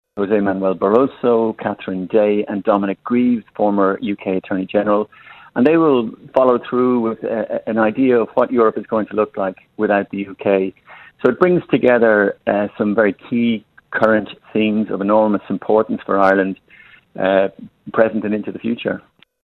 Director General of the IIEA, Barry Andrews says international speakers will examine how the EU will look without the UK: